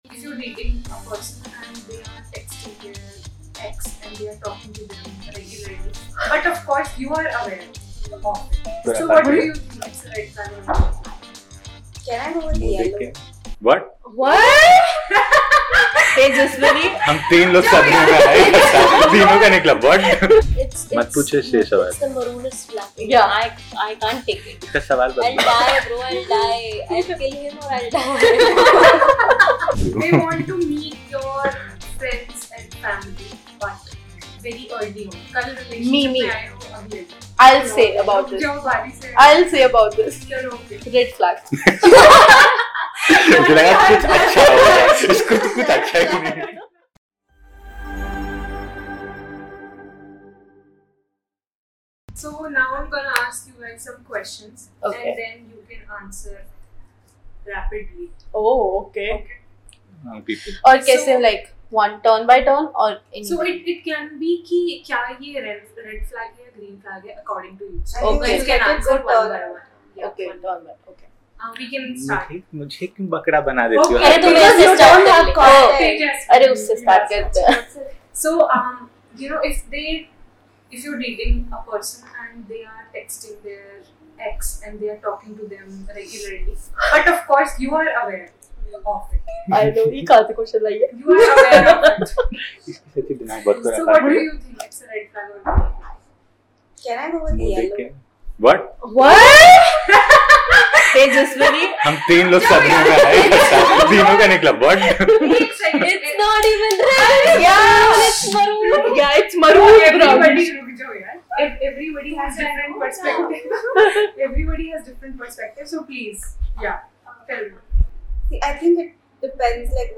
The panel shares raw perspectives on the realities of modern love.